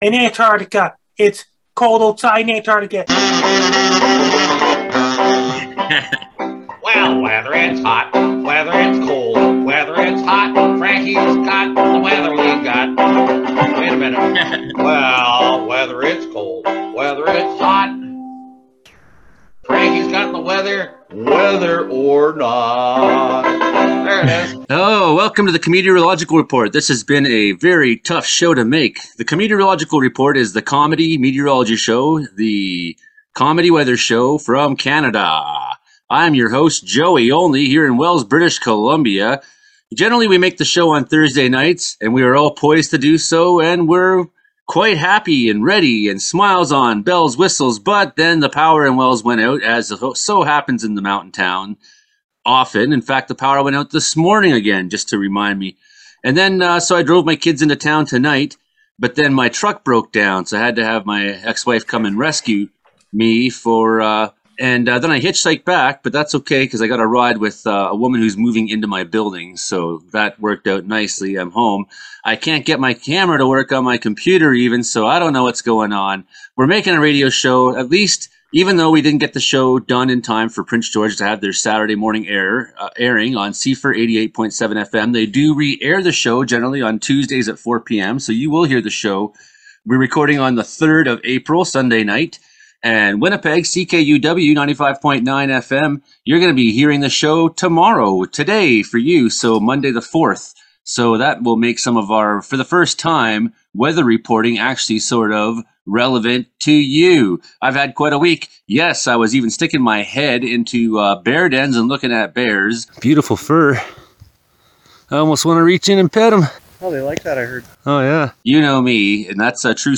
mix comedy and meteorology with their panel of Comedeorologists